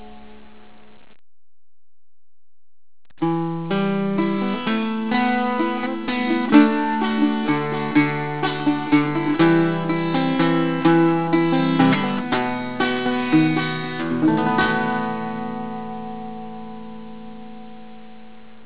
CITTERN
Cittern Sound Clips